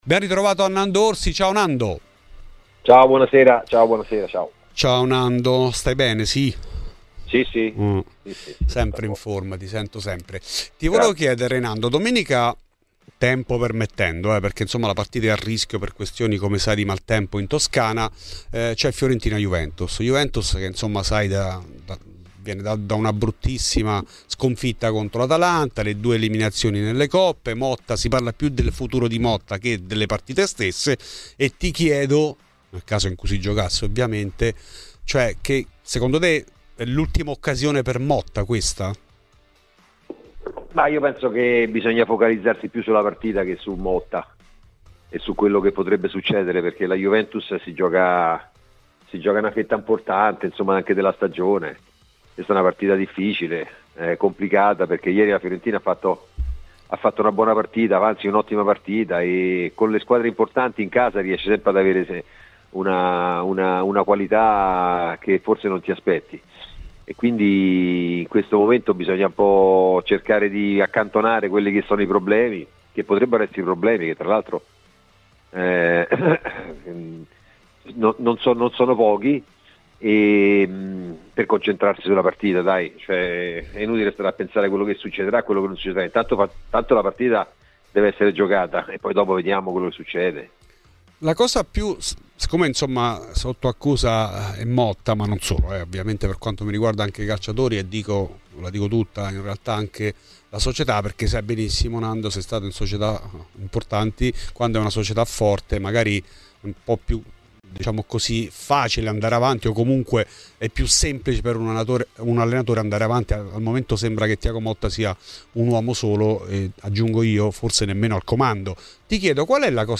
L'ex portiere Fernando Orsi è intervenuto a Radio Bianconera per parlare di Fiorentina-Juventus: